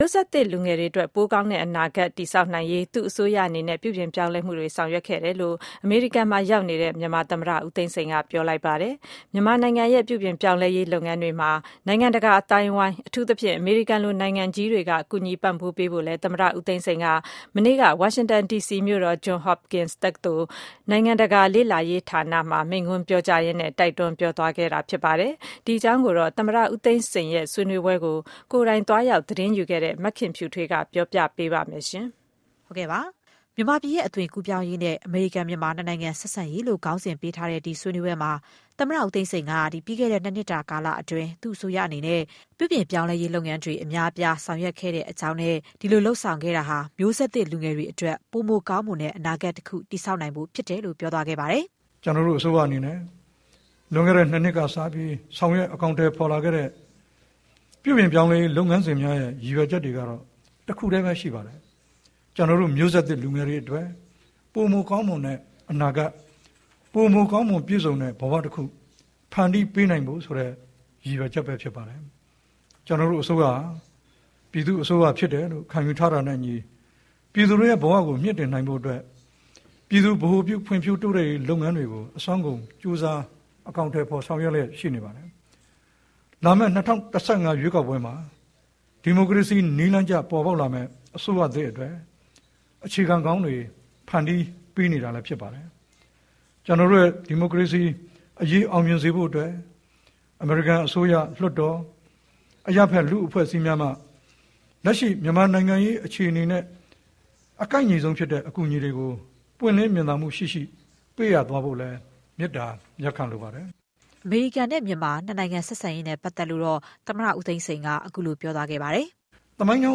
Johns Hopkins မှာ ဦးသိန်းစိန်ပြောဆို